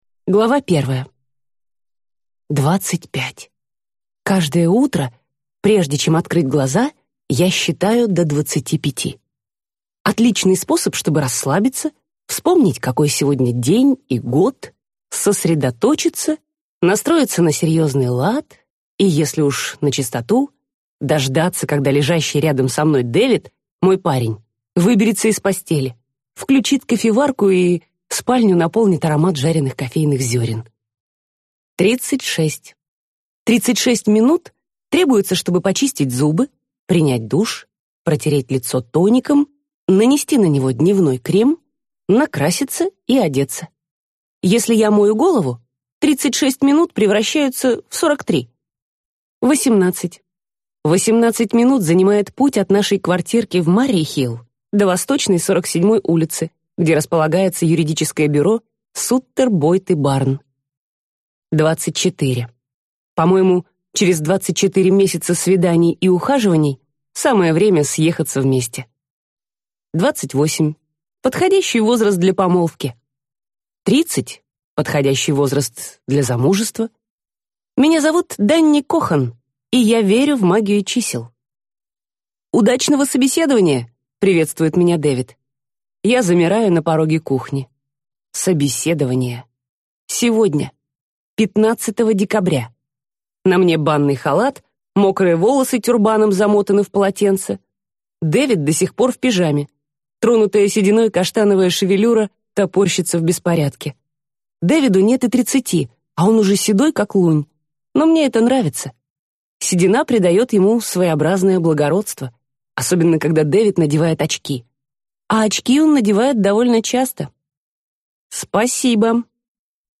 Аудиокнига Через пять лет | Библиотека аудиокниг